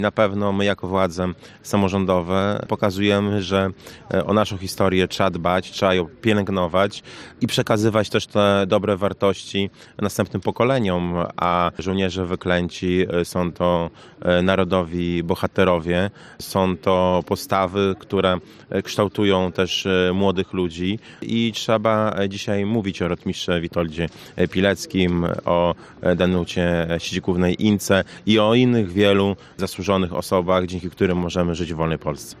Historia naszego kraju powinna służyć młodym ludziom podsumowuje Prezydent Łomży, Mariusz Chrzanowski: